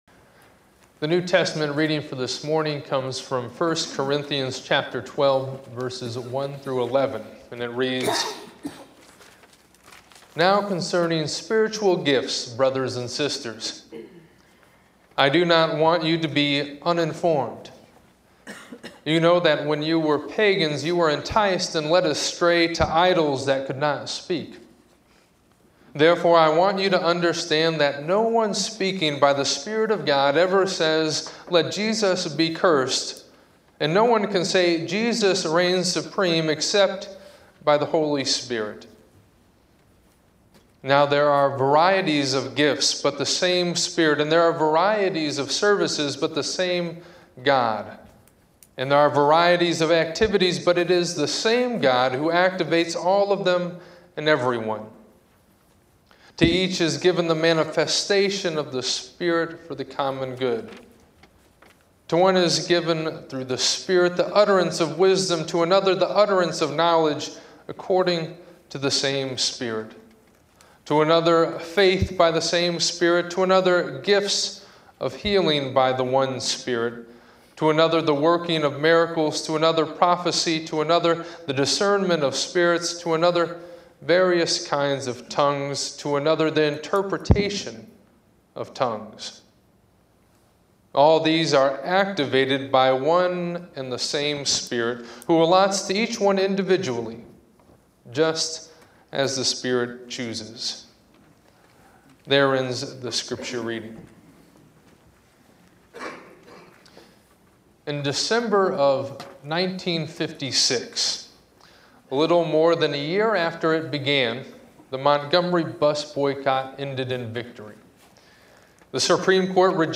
To listen to this sermon given on Martin Luther King, Jr. Sunday, click here.